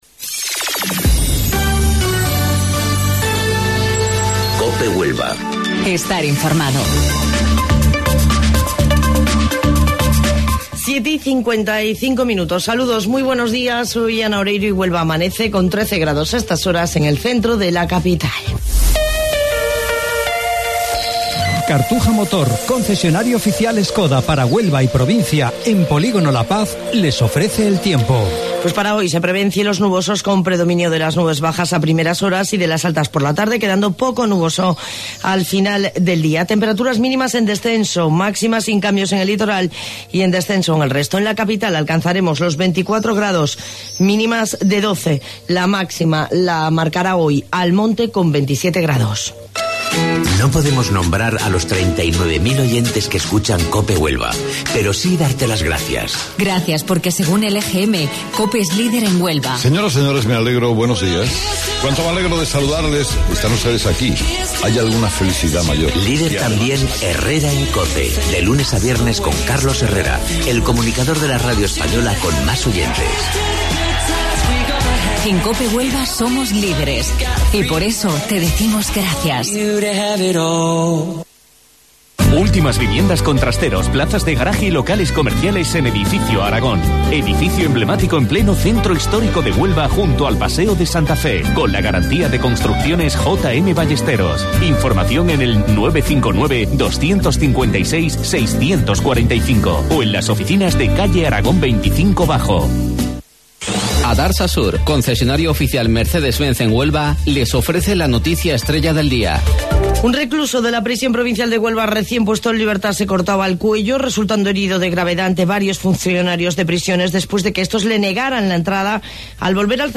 AUDIO: Informativo Local 07:55 del 6 de Mayo